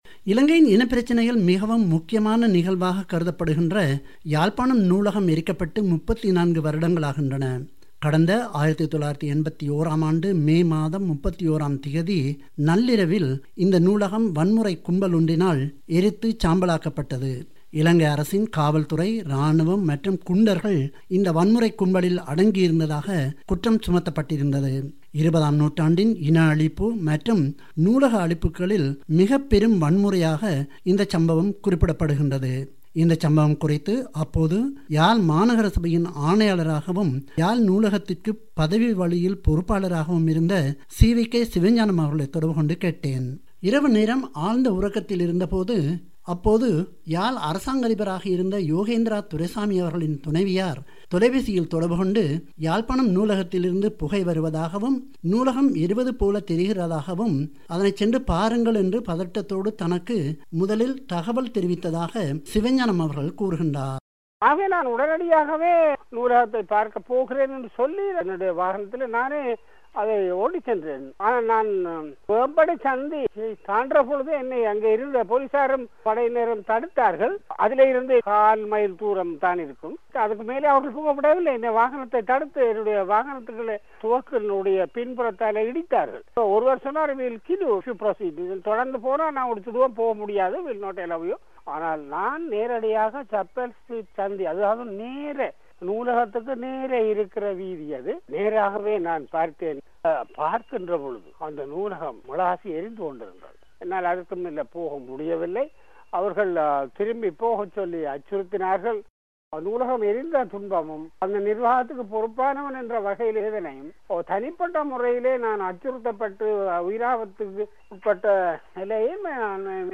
நூலகம் எரிக்கப்பட்டபோது யாழ். மாநகரசபையின் ஆணையாளராகவும் நூலகத்தின் பொறுப்பதிகாரியாகவும் இருந்த, தற்போதைய வடக்கு மாகாணசபையின் தற்போதைய அவைத் தலைவர் சி.வி.கே. சிவஞானம் பிபிசி தமிழோசையுடன் பகிர்ந்துகொண்ட நினைவலைகளை நேயர்கள் கேட்கலாம்.